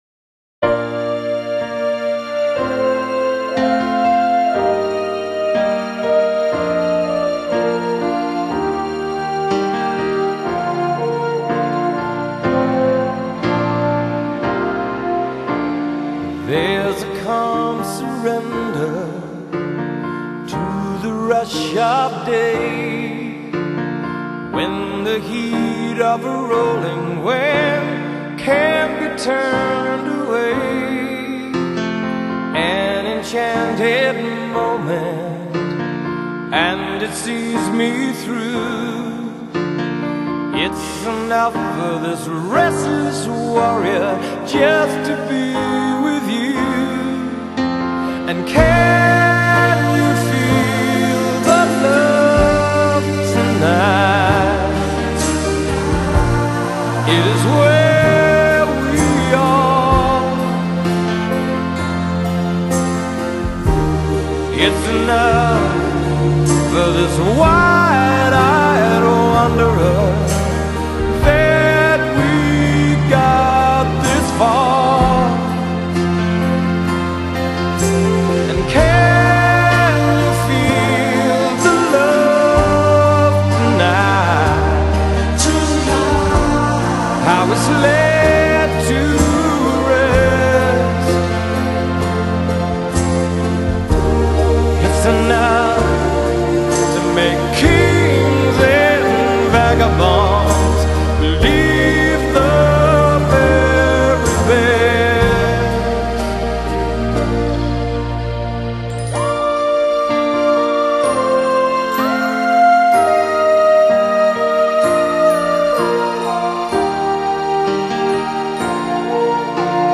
Genre: Pop, Soundtrack